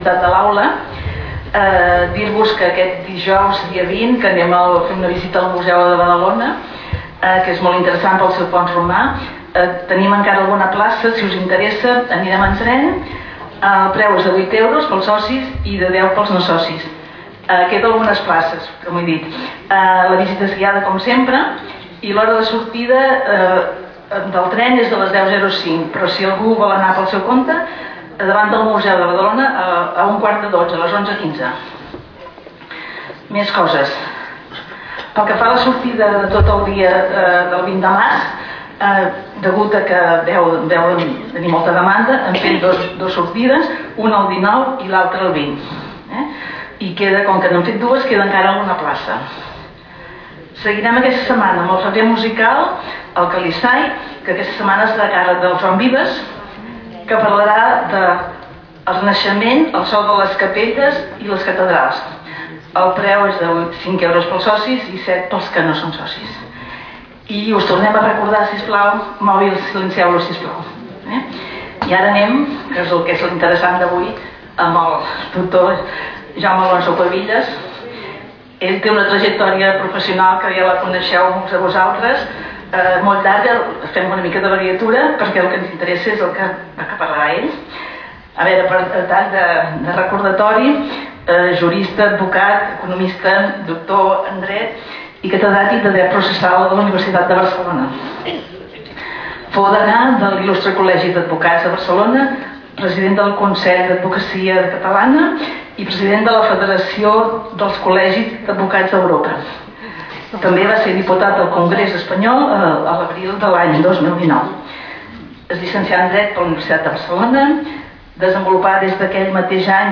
Categoria: Conferències